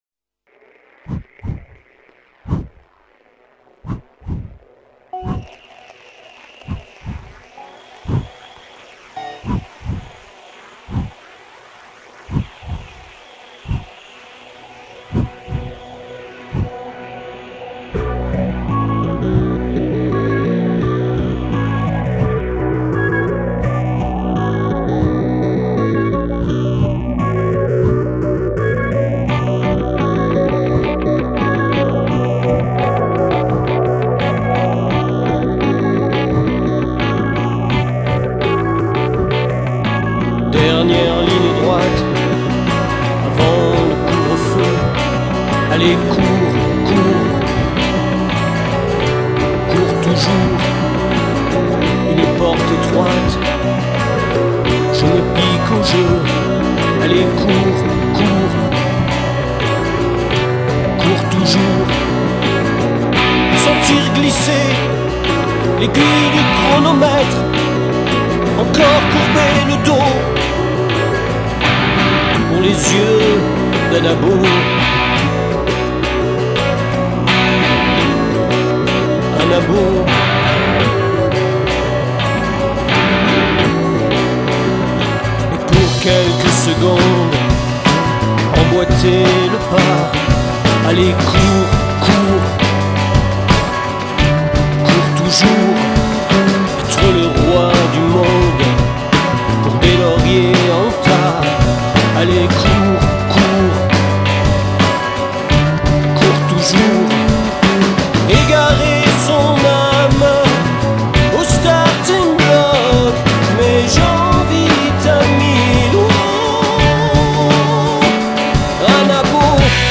groupe de rock aurillac